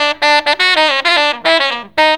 JAZZY A.wav